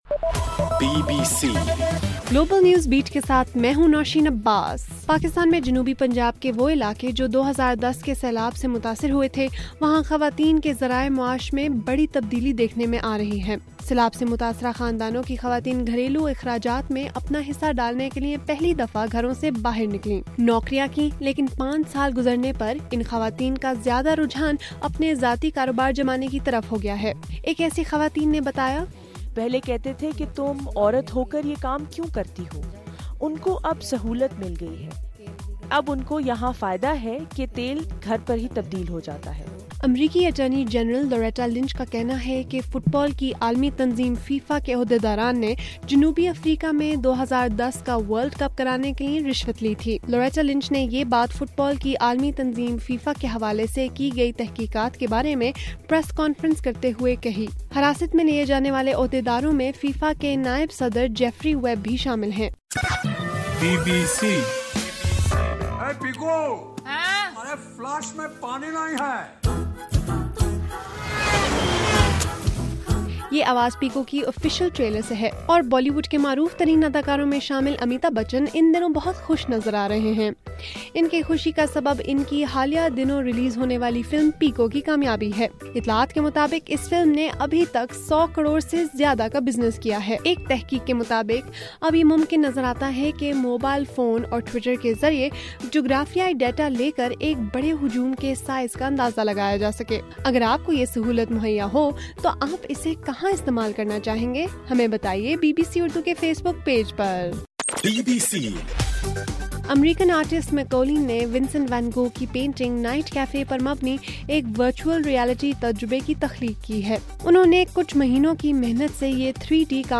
مئی 27: رات 12 بجے کا گلوبل نیوز بیٹ بُلیٹن